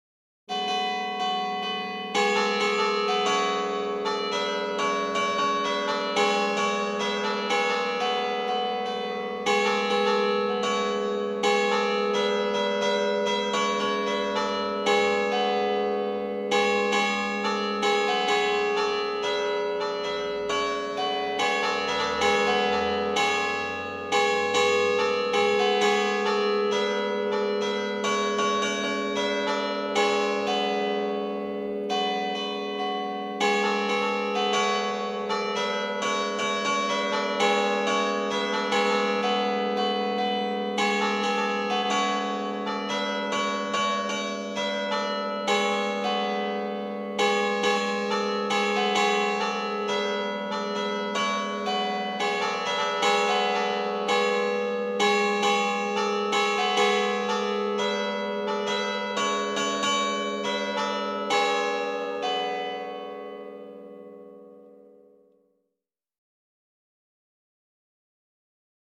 Carillon de Champéry: Marche des rois – March of the kings